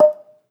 plt.title('Marimba')
marimbaNote.wav